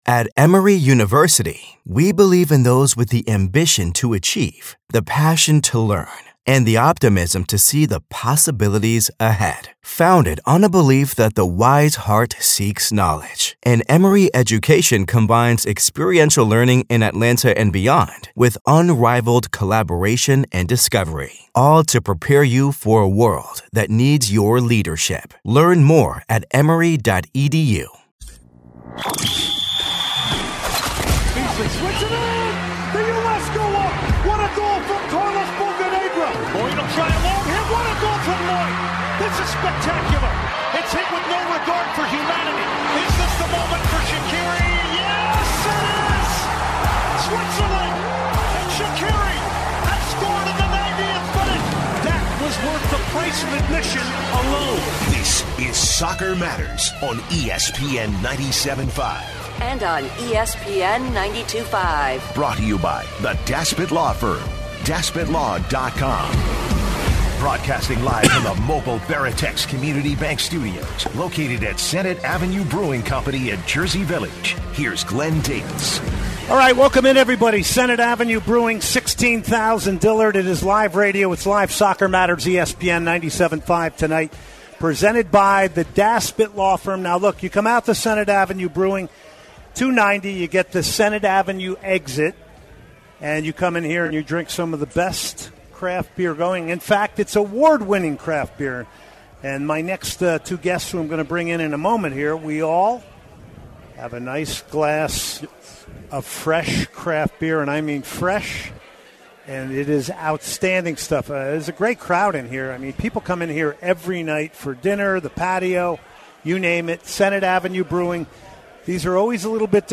Live from Senate Brewing